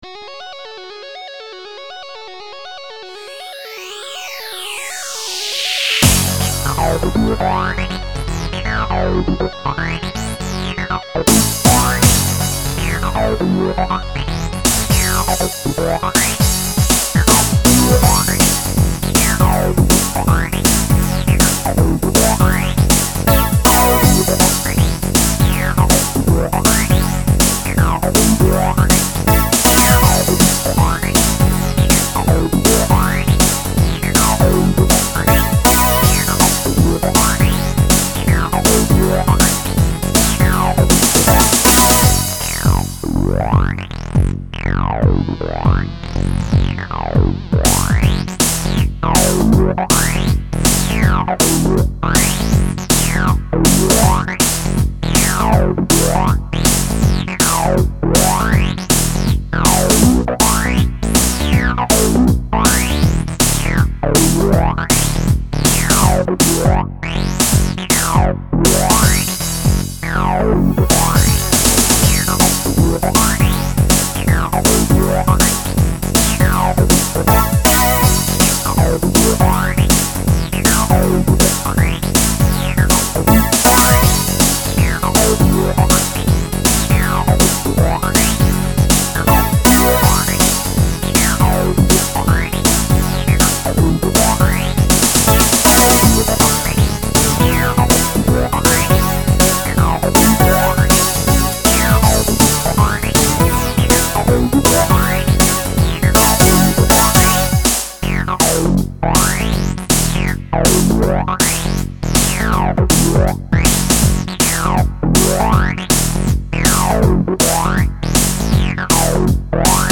Love Rocket [Tech'n'Roll
Hat ungemein viel groove das Teil. Die Abmische und der Drucklose Sound erinnern mich aber ein wenig zu sehr an die früheren Zeiten.
Die String, der da bei 3min so ausflippt ist genial.
wuerd astrein auf ein 2d-jump'n'run passen! super synthsolo mittendrin. eingepackt.
yeah, das is' echter totaldaddelsound, klar liegt bei den SID-sounds ja auch nahe:
Naja also drucklos find ich ihn jetzt nicht unbedingt... okay die Bassdrum ist nicht überfett, aber das war Absicht, da bei Rockmusik die Bassdrum ja nicht im Vordergrund steht, und der Beat hier ja sehr sehr rockorientiert ist...
auf jeden fall sehr geiler game-sound,
was mir am besten gefällt, ist der pop-synth,
der die bassline imemr mit 2 noten begleitet.